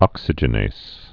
(ŏksĭ-jə-nās, -nāz)